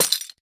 Minecraft Version Minecraft Version latest Latest Release | Latest Snapshot latest / assets / minecraft / sounds / block / chain / break4.ogg Compare With Compare With Latest Release | Latest Snapshot
break4.ogg